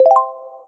Affirmative cue, potential alternative entry chime.